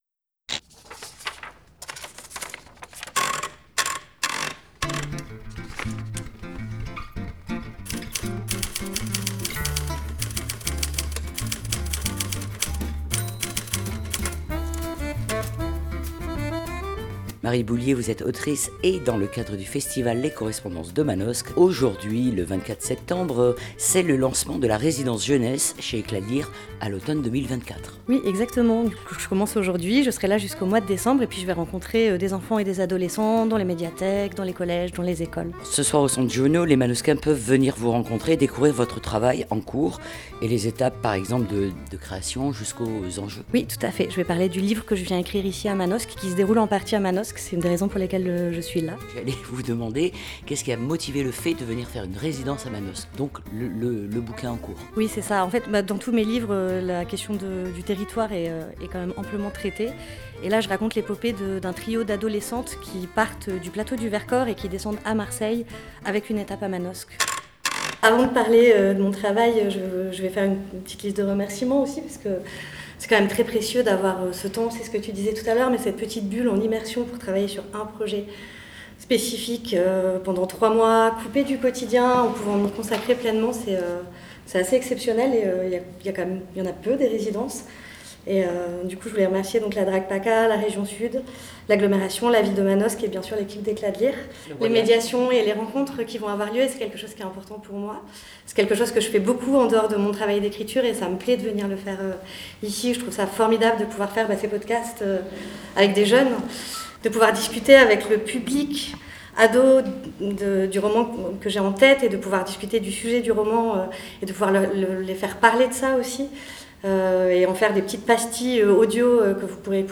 Dans le cadre du festival Les Correspondances de Manosque .